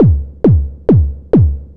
K-7 Kick.wav